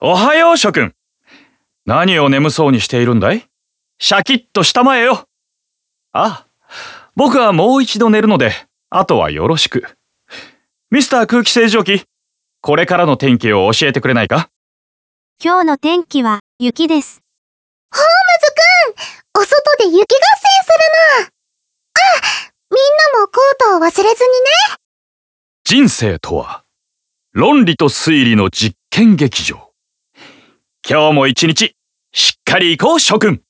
こちらは天気予報です。
今、天気が「雪」ってしゃべっていましたが、これってどういう仕組みですか？
雨だったら傘がいるよとか、荒れそうだったら気をつけてねなど、天気に合わせたボイスが流れます。